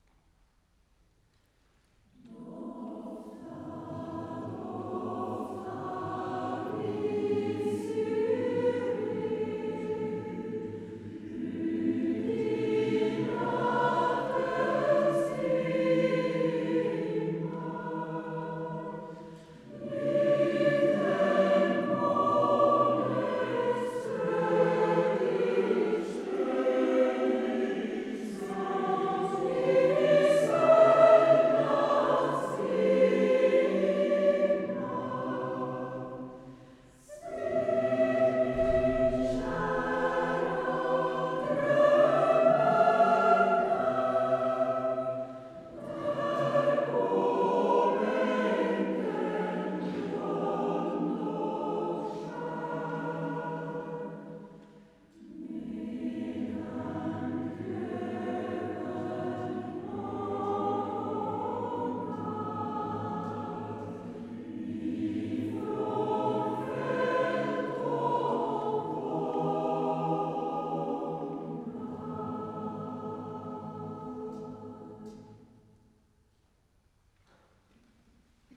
Här finns prov på inspelningar av ljud, video och bilder från körens konserter